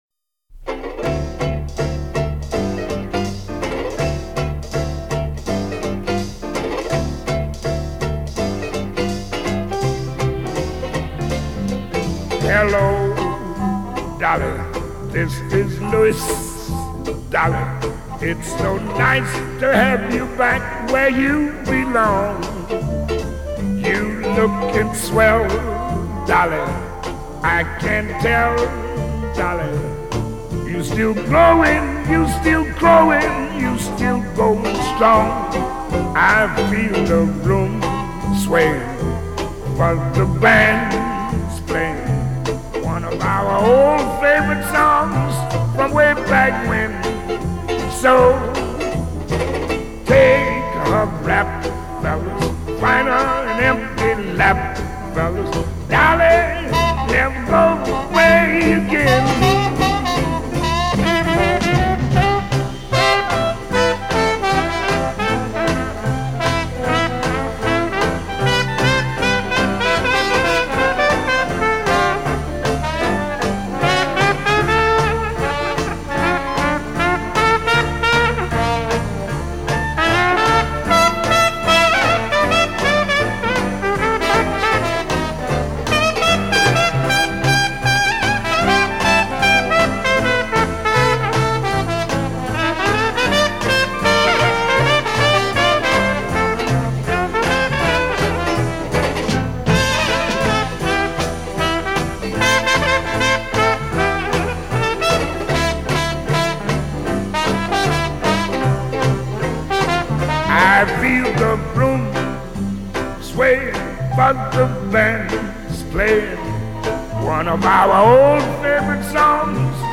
风格流派：Jazz